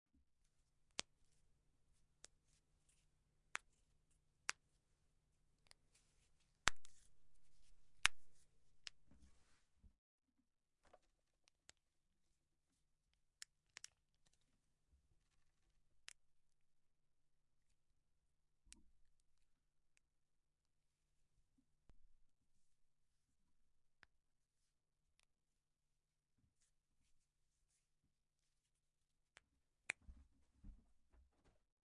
嘎吱嘎吱